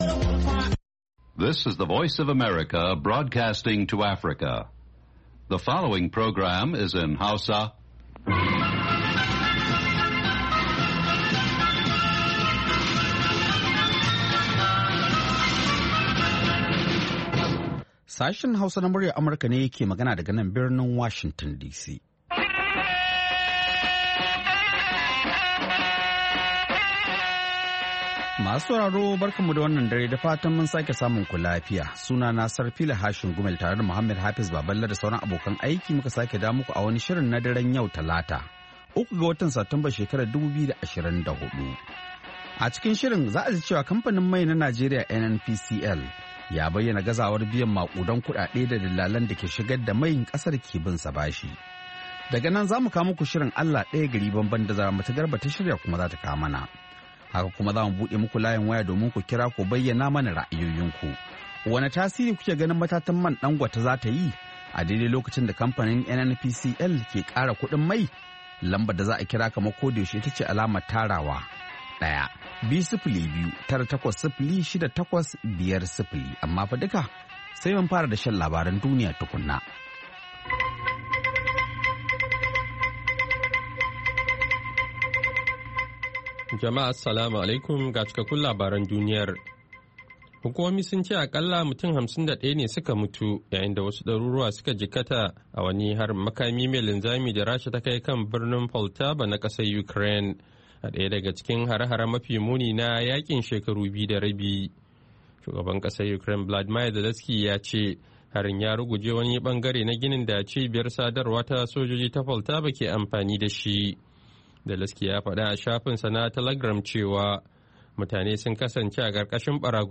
A shirin namu na karfe 9 da rabi agogon Najeriya da Nijar, zaku ji labarai na yadda duniyar ta yini da rahotanni da dumi-duminsu, sannan mu kan bude muku layuka domin ku bugo ku bayyana mana ra’ayoyinku kan batutuwan da suka fi muhimmanci a wannan rana, ko kuma wadanda ke ci muku tuwo a kwarya.